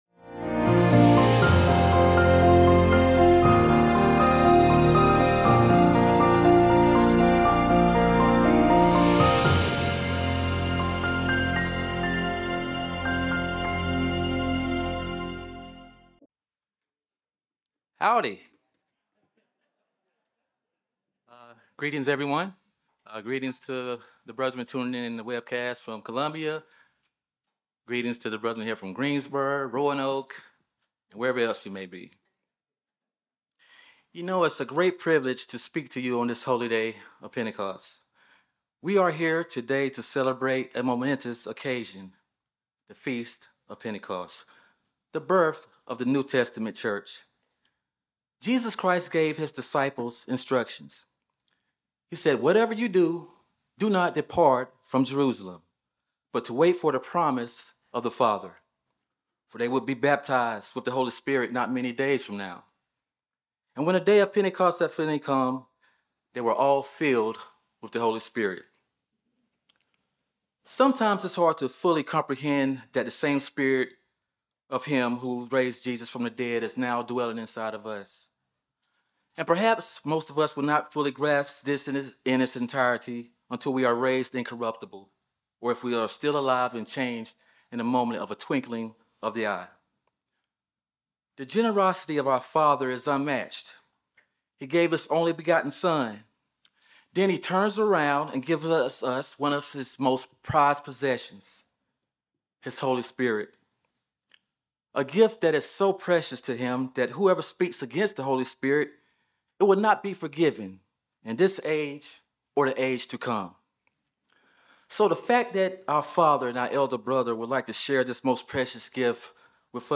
Sermons
Given in Charlotte, NC Columbia, SC Hickory, NC